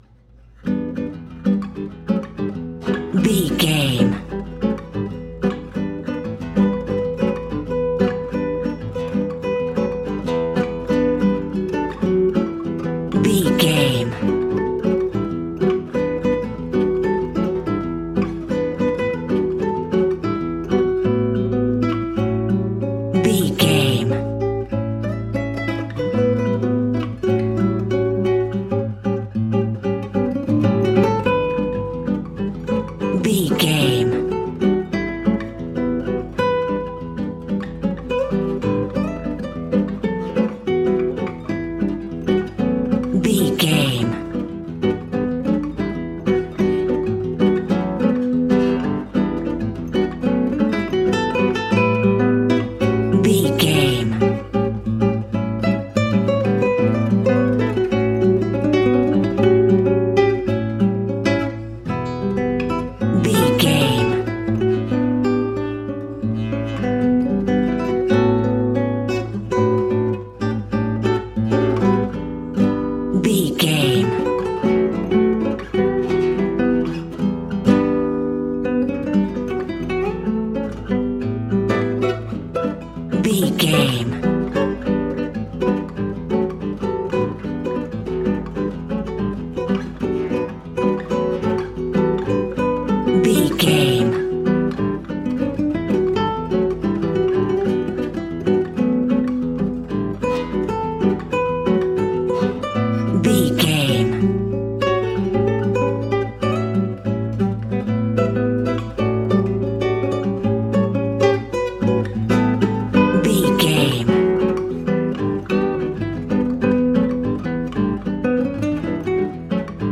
Aeolian/Minor
percussion spanish guitar